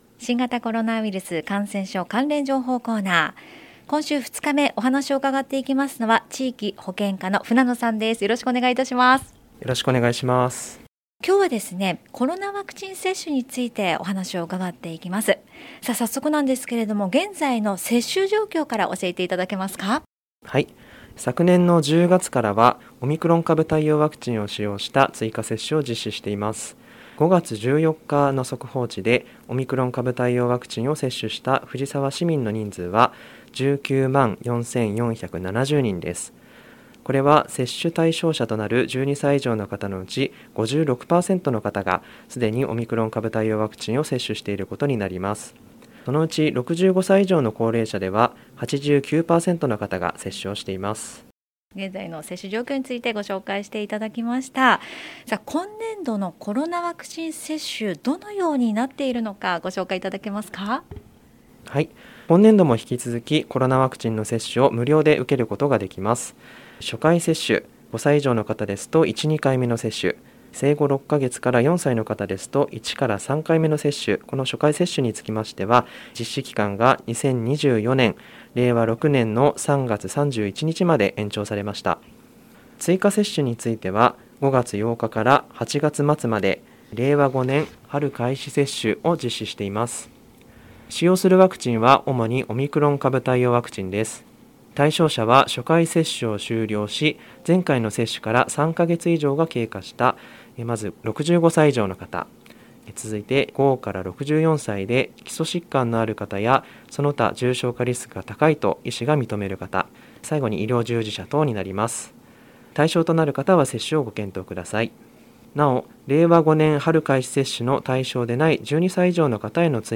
令和5年度に市の広報番組ハミングふじさわで放送された「新型コロナウイルス関連情報」のアーカイブを音声にてご紹介いたします。